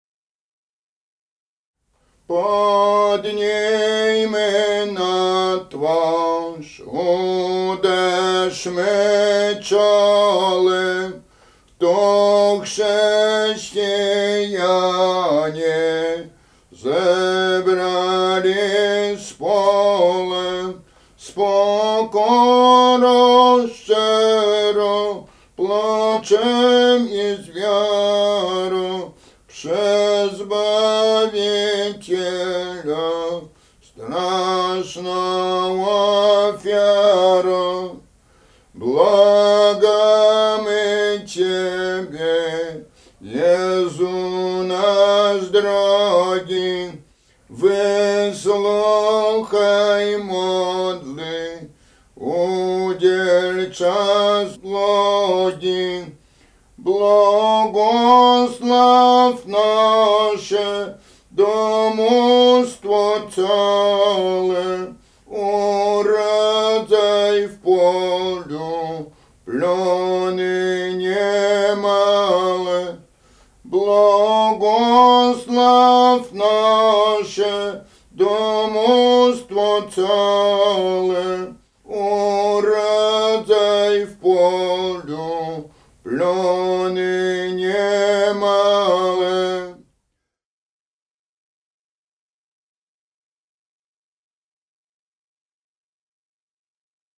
Suwalszczyzna
województwo podlaskie, powiat augustowski, gmina Lipsk, wieś Jasionowo
W wymowie Ł wymawiane jako przedniojęzykowo-zębowe
Suplikacja na św. Marka
nabożne